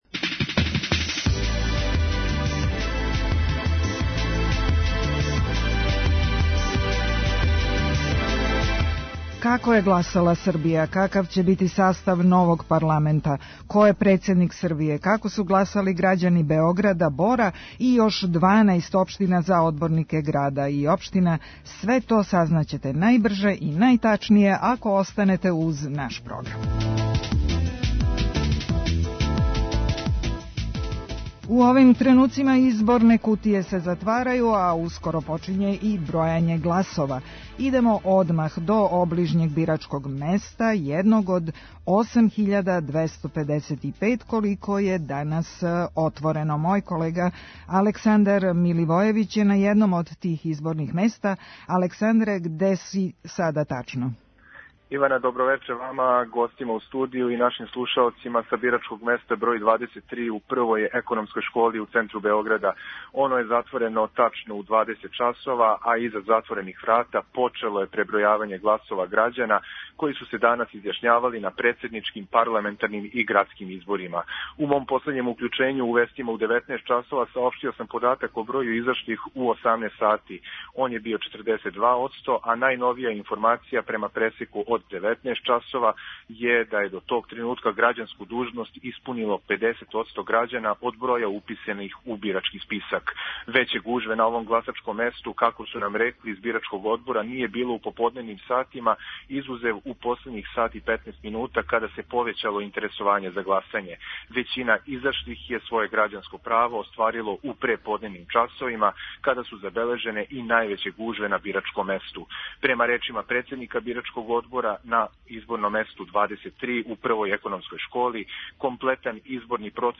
Репортери Радио Београда 1 су у изборним штабовима, Републичкој и Градској изборној комисији и CESID-u.